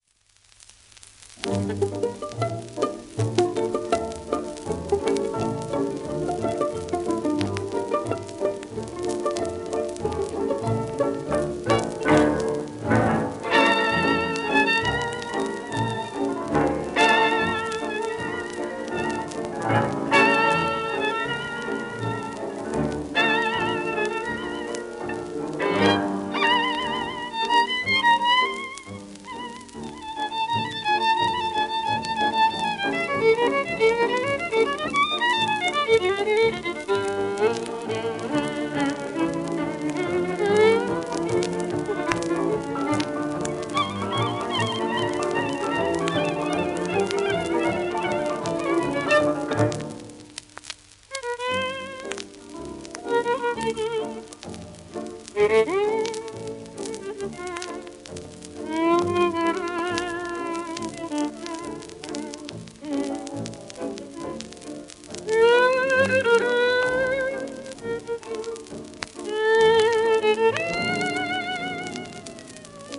w/オーケストラ
1935年録音